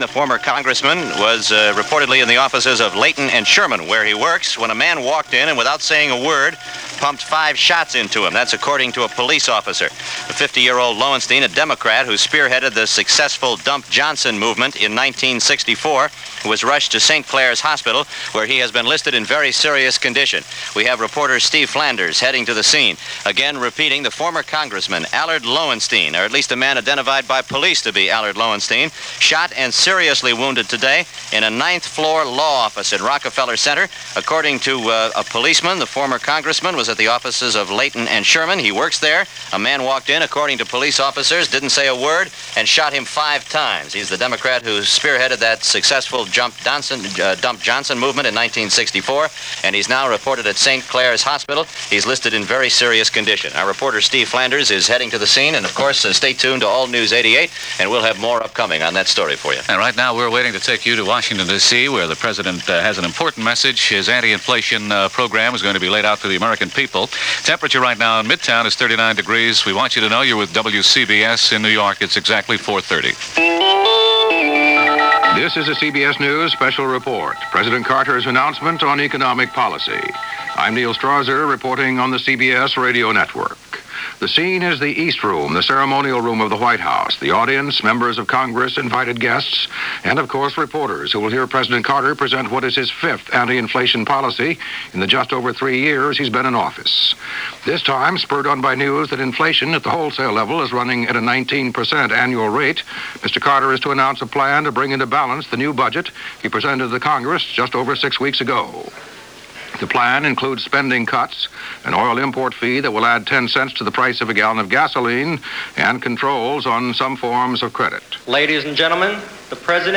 March 14, 1980 - Fixing a flabby economy - Jimmy Carter's Economic address to the nation - Past Daily Reference Room - Presidential addresses
March 14, 1980 – President Carter’s Address on the Economy +news of Allard Lowenstein shooting – CBS Radio